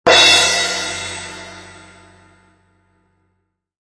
Звуки тарелки
На этой странице собраны звуки тарелок – яркие, резонансные и динамичные.
Тарелка музыкальный инструмент вариант 2